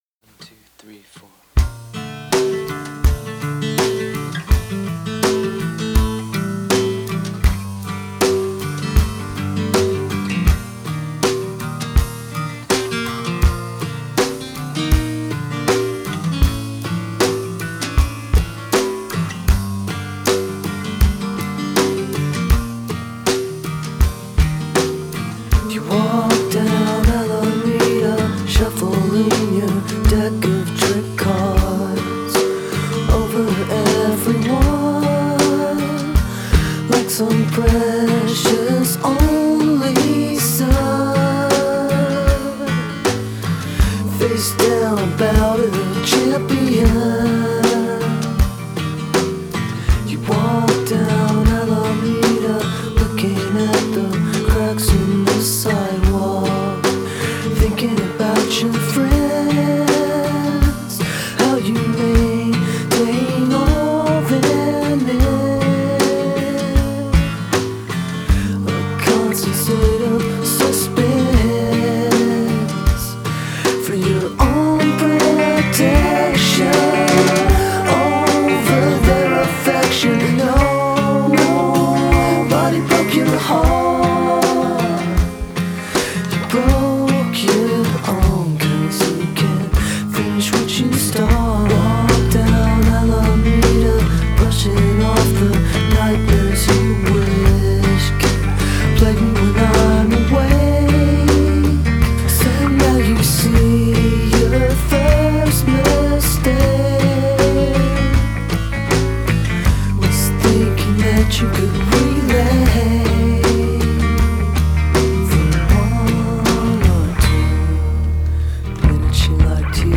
Genre: Indie Rock / Singer-Songwriter / Acoustic